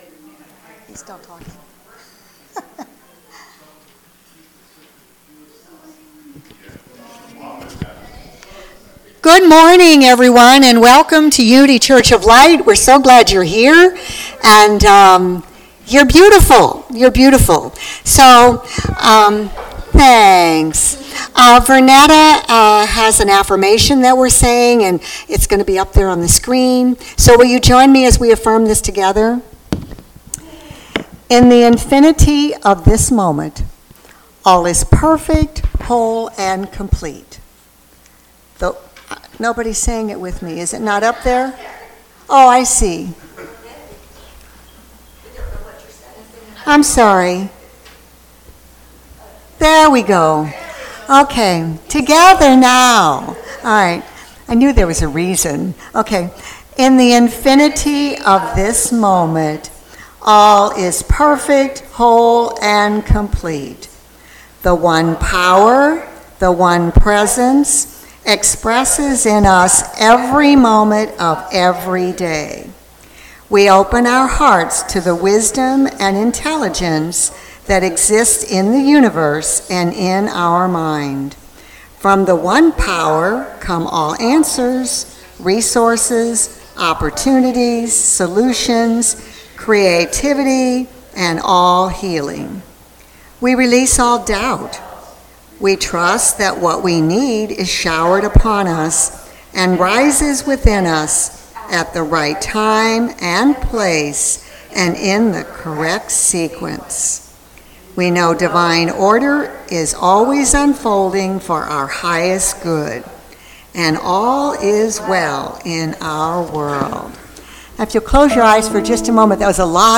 Minister Emeritus Series: Sermons 2023 Date